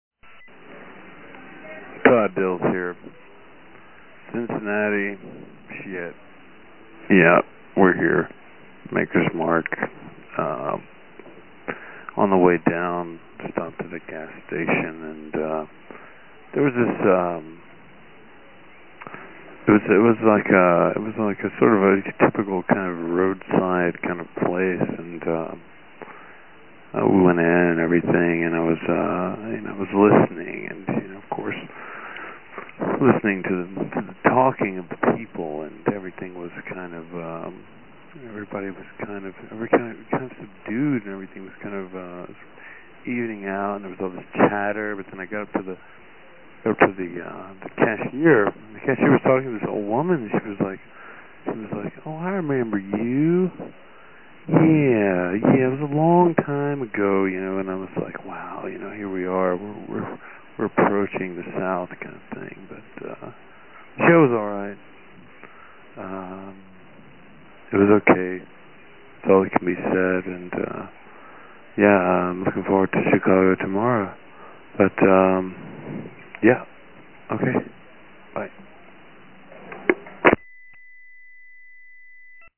Every day they were on the road, one of them called and left a message about something that happened that day, and they were posted below.